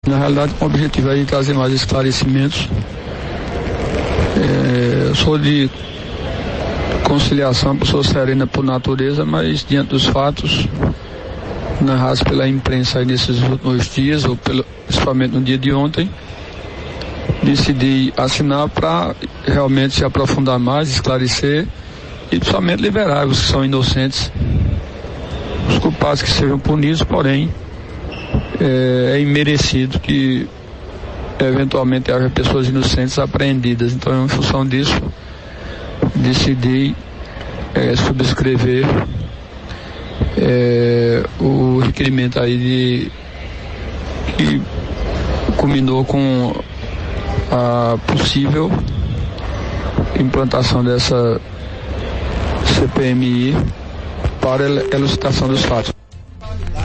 As declarações dos dois parlamentares repercutiram em entrevista à Arapuan FM.